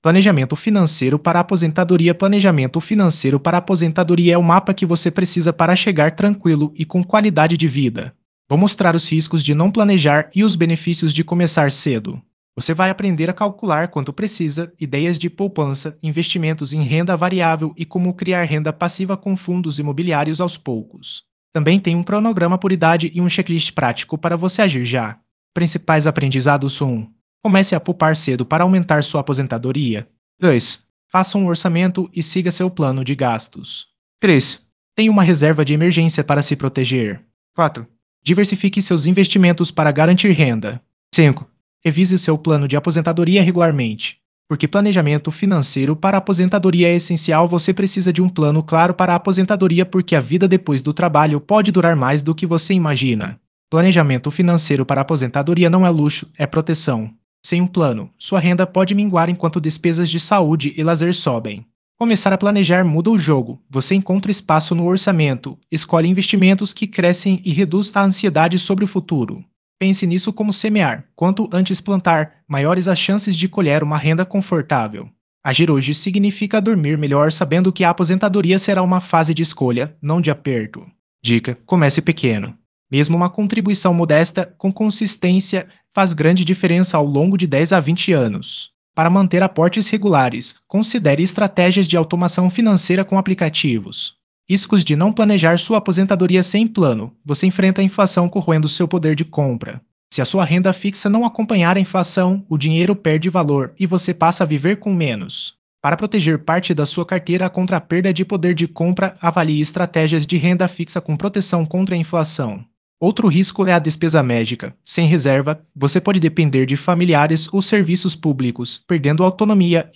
Ouça O Artigo via Áudio (Acesso Simples & Rápido)